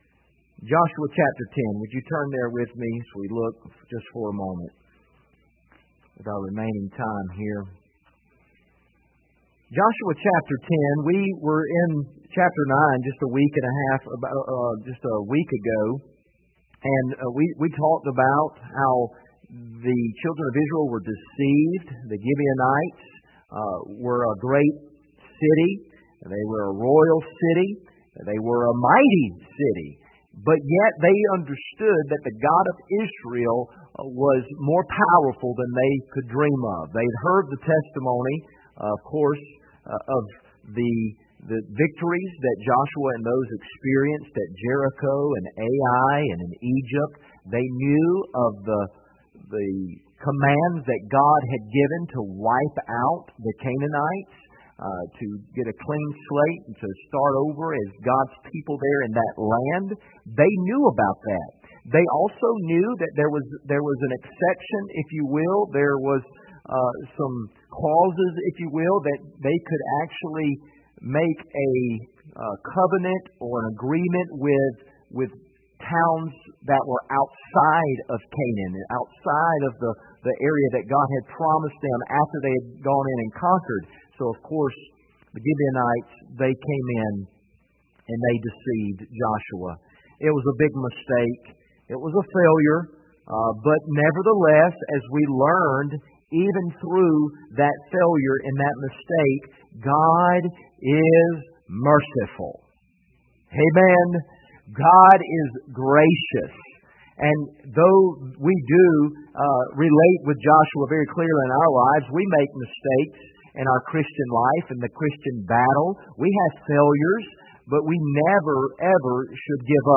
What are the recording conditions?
Passage: Joshua 10:1-14 Service Type: Sunday Evening